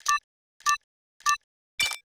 Countdown (4).wav